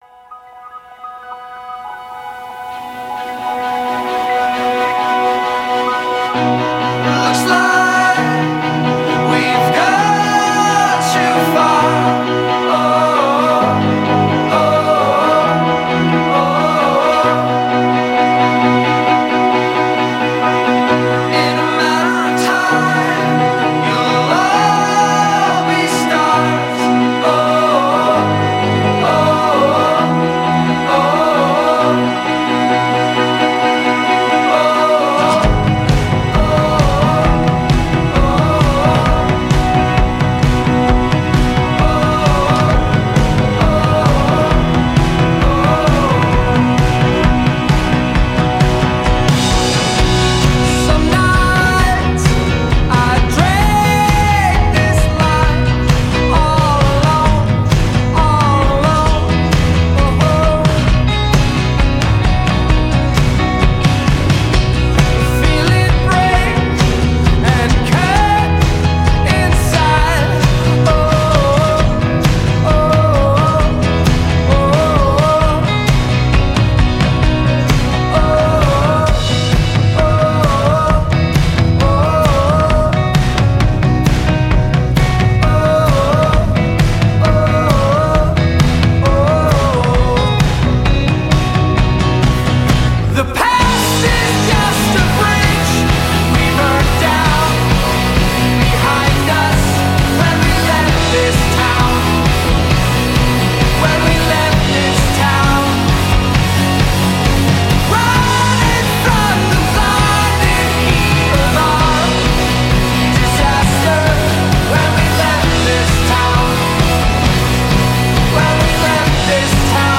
本期音乐为合成器流行（Synthpop）音乐专题。